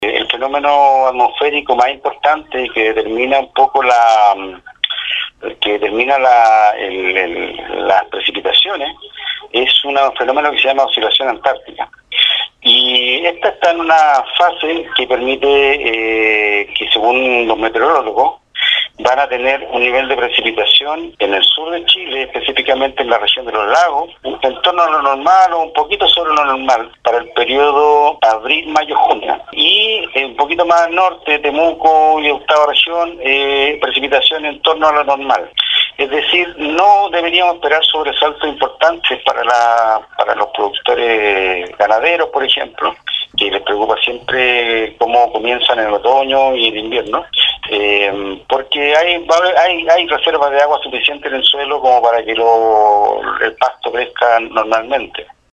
en conversación con Radio SAGO señaló que de acuerdo a la influencia del fenómeno denominado “Oscilación Antártica” deberían producirse en el sur del país